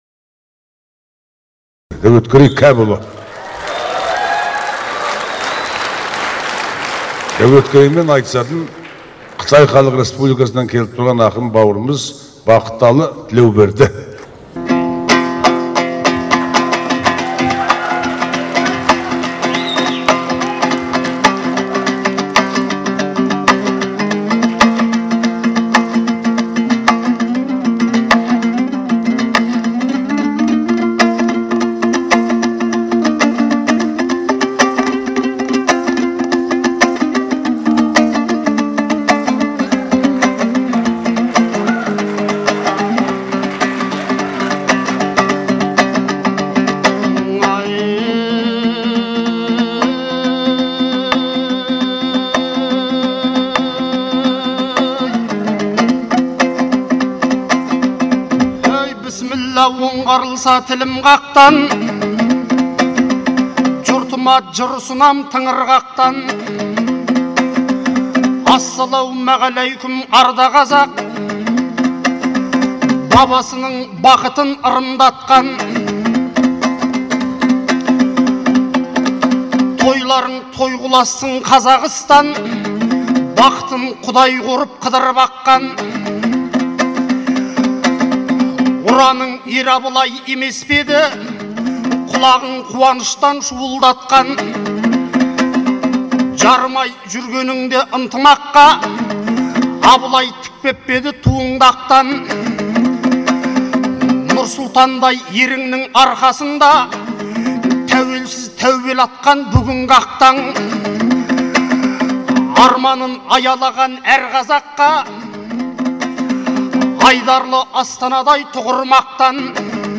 Астанадағы айтыс